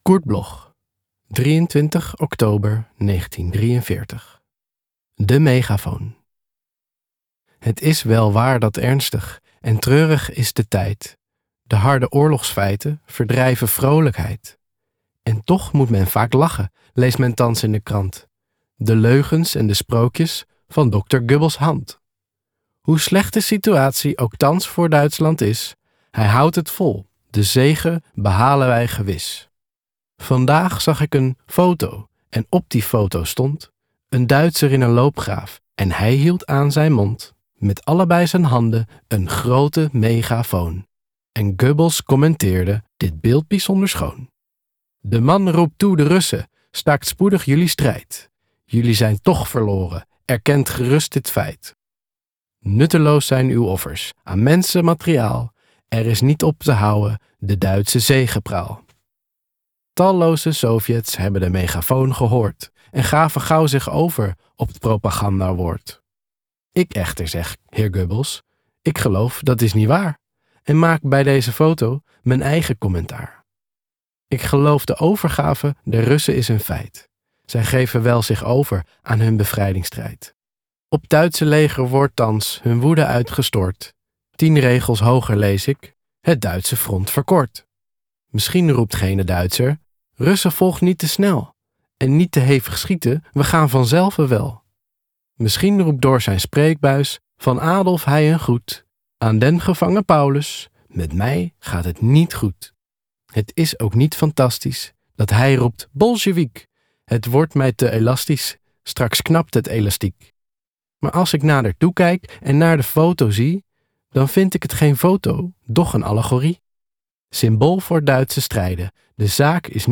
Recording: MOST, Amsterdam · Editing: Kristen & Schmidt, Wiesbaden